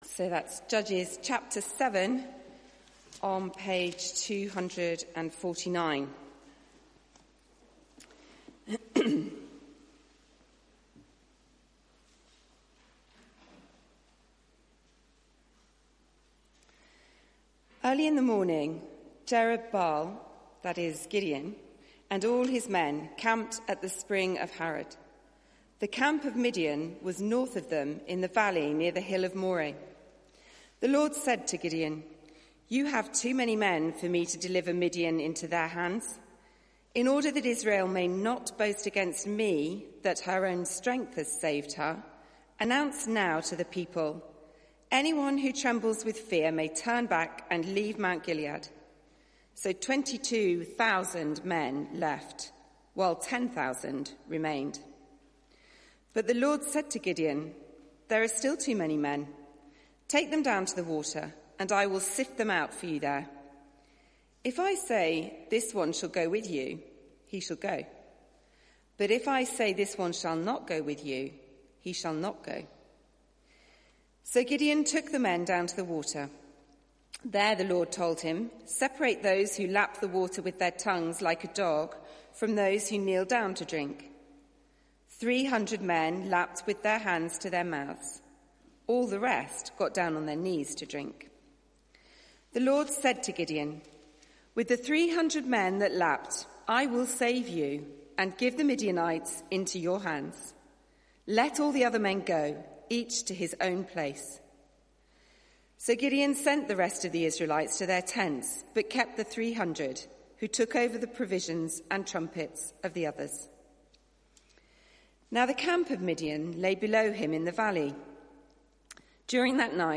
Media for 4pm Service on Sun 03rd Nov 2019 16:00 Speaker
Sermon Search the media library There are recordings here going back several years.